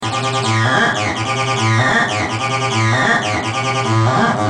Sons et loops gratuits de TB303 Roland Bassline
Basse tb303 - 45